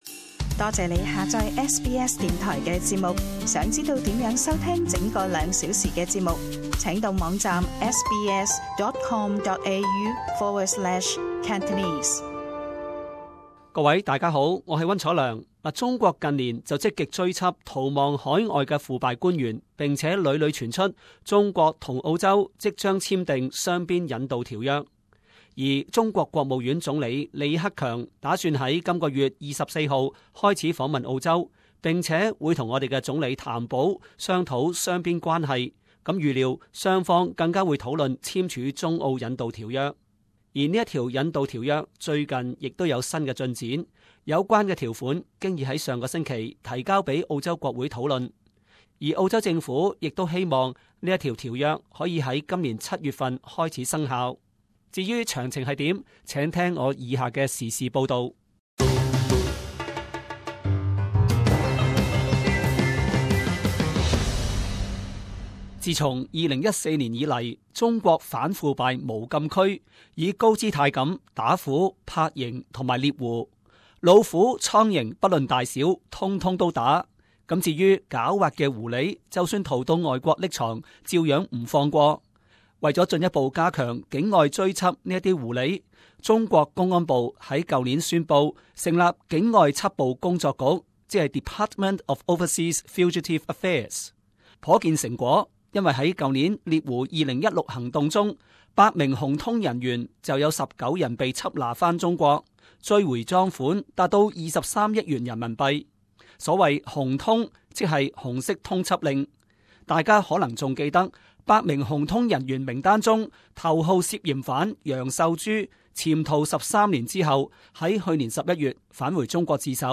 【時事報導】 中國引渡條約可望本年内生效